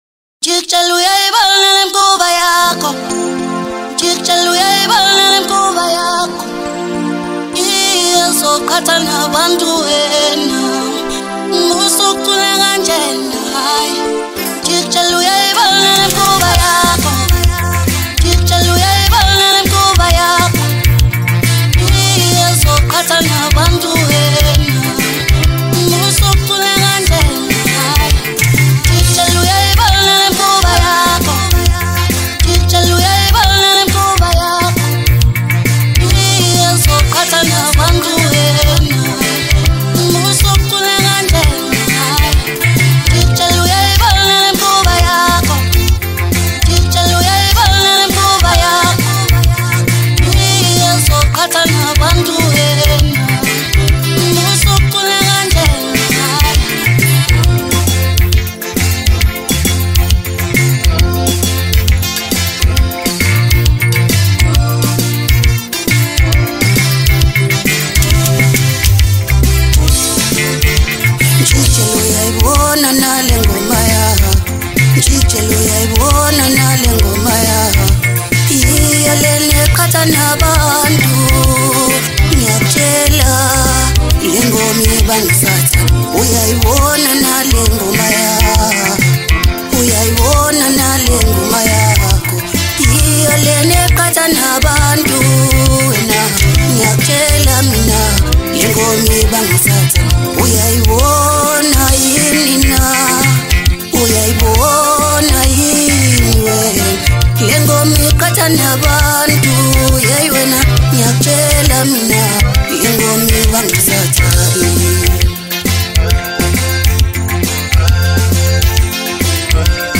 Maskandi